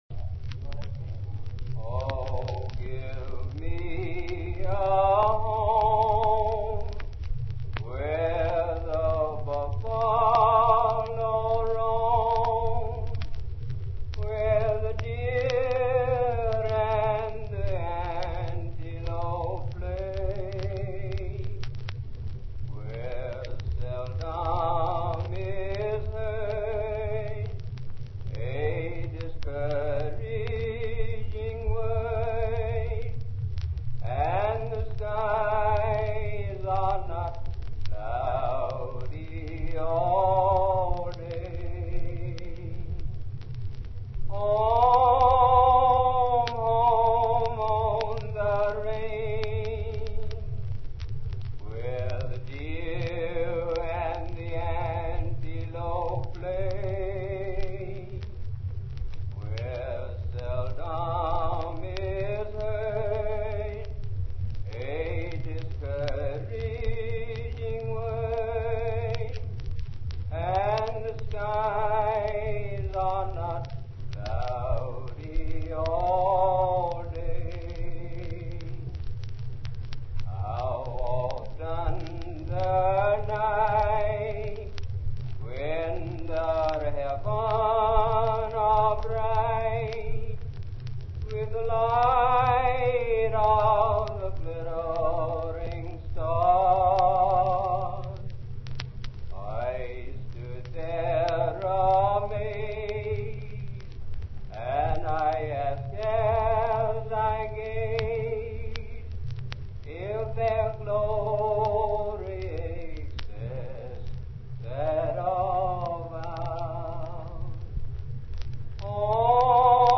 Prison recording
Location: State Farm, Raiford, Union County, Florida
Traditional Cowboy Song - Author Unknown